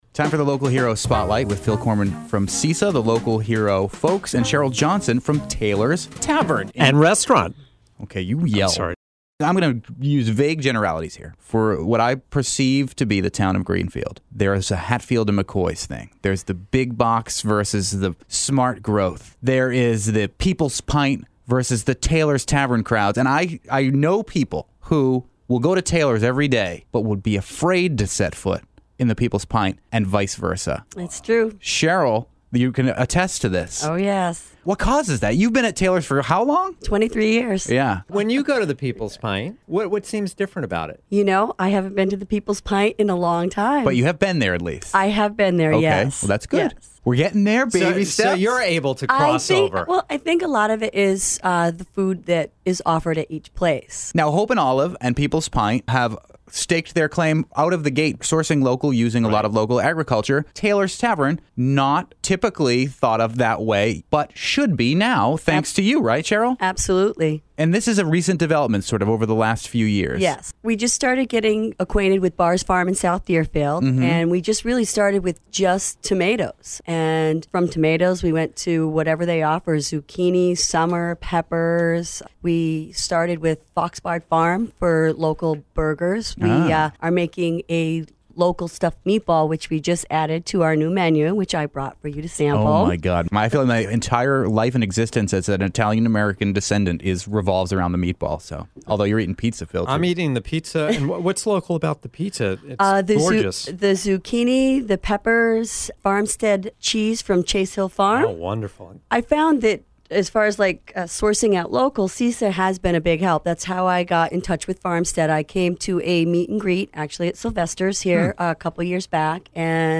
Interview: Taylor’s Tavern